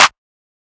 BWB XCLUSIVE 2 CLAP (10).wav